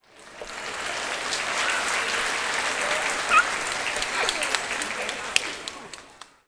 PEOP_Clap_040.AIF